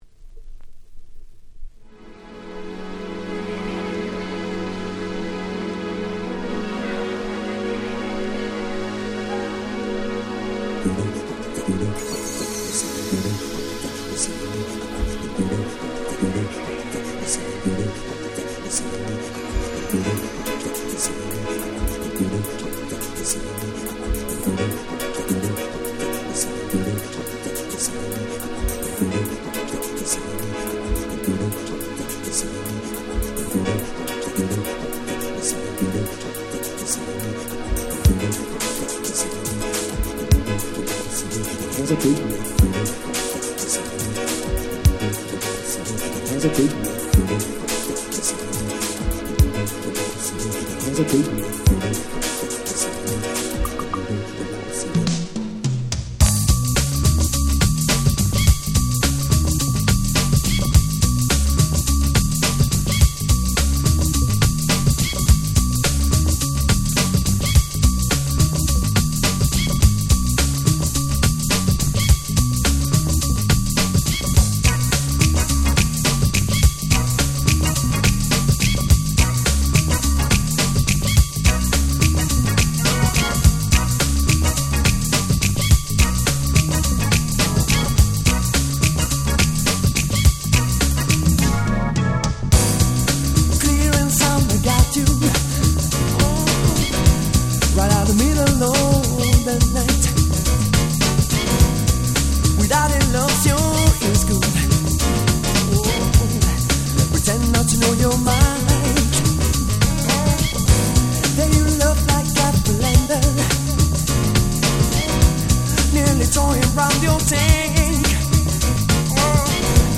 91' Very Nice Ground Beat !!
グラビ グランド イタロハウス Grand 90's R&B